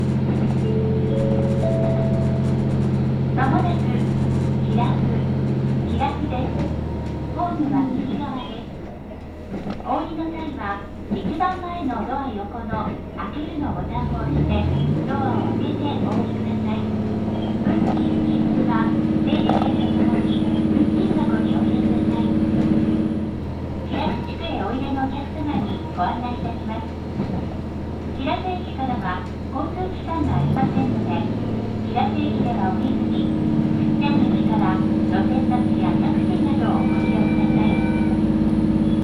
函館本線の音の旅｜比羅夫駅到着アナウンスと珍しい下車案内【H100系普通列車】
今回の「音の旅」では、函館本線の小さな駅 比羅夫駅 に到着する際の車内アナウンスを収録しました。
収録した列車は、JR北海道の最新型気動車 H100系
静かな車内に響くアナウンスと、減速時の柔らかな走行音が、